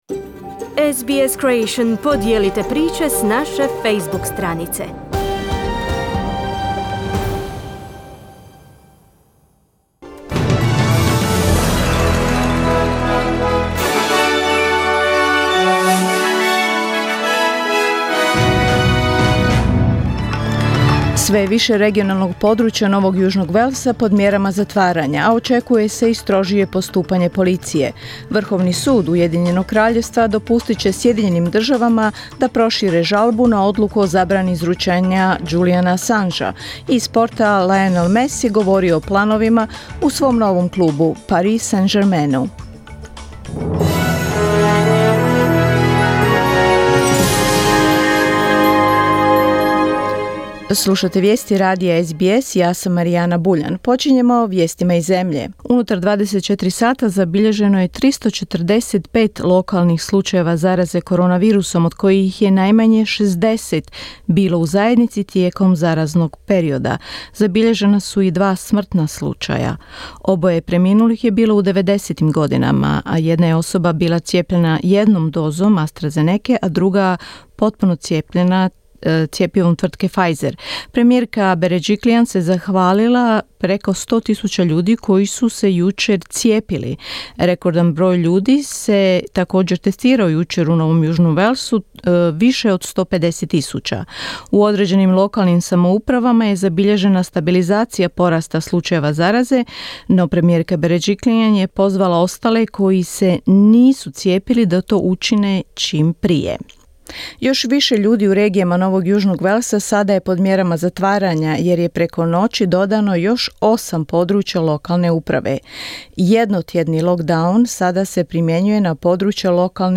Vijesti radija SBS na hrvatskom jeziku